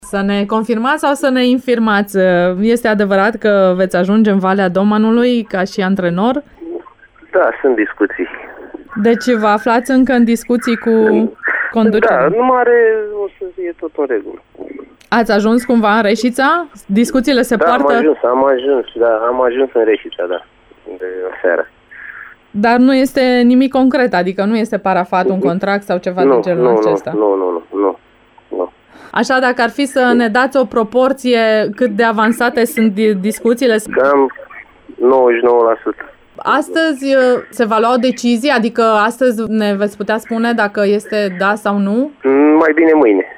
În exclusivitate pentru Radio Reșița, Daniel Oprița a declarat: